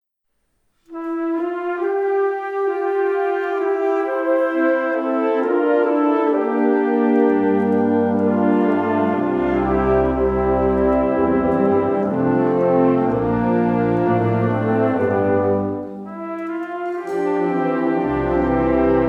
Catégorie Harmonie/Fanfare/Brass-band
Sous-catégorie Musique de concert